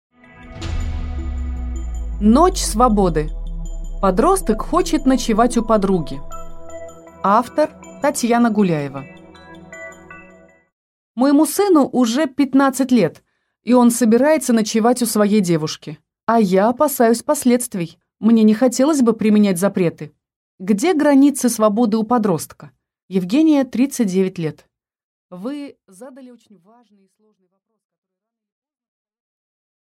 Аудиокнига Ночь свободы | Библиотека аудиокниг